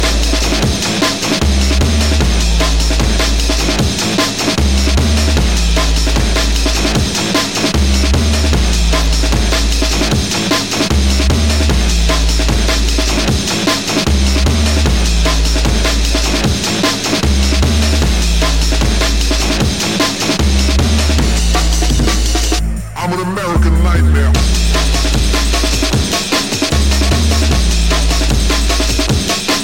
TOP >Vinyl >Drum & Bass / Jungle
Jungle remix